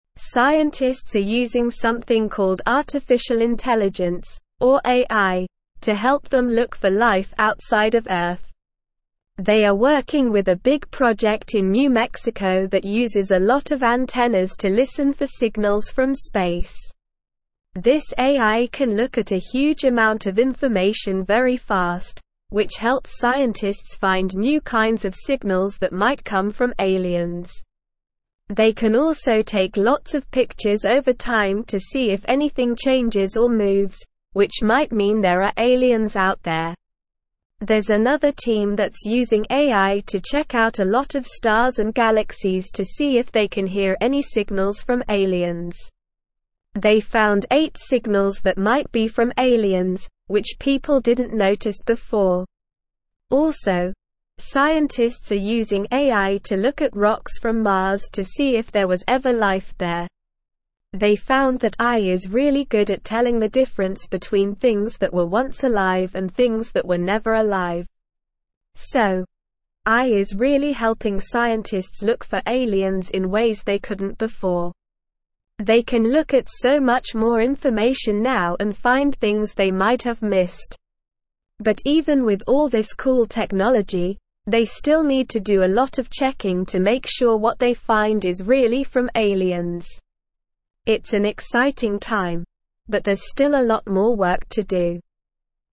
Slow
English-Newsroom-Lower-Intermediate-SLOW-Reading-Smart-Computers-Help-Scientists-Look-for-Space-Life.mp3